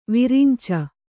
♪ virica